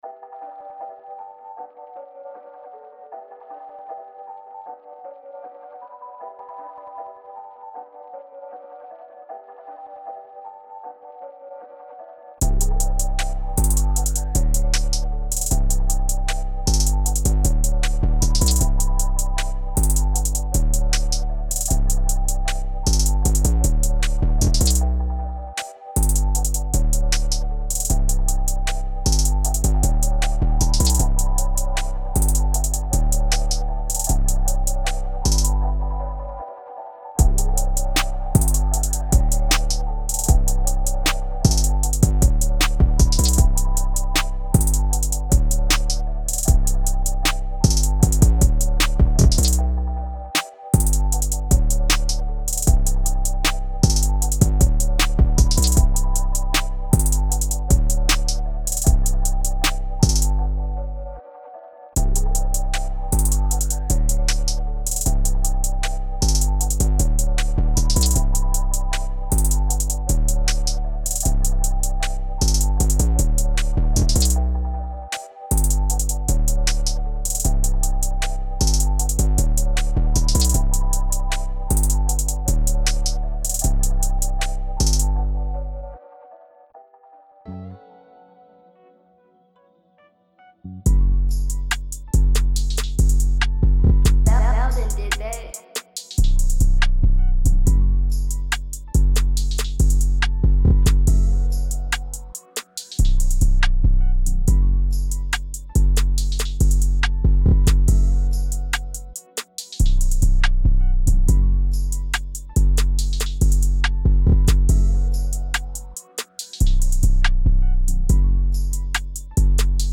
Hip HopTrap
Hip Hop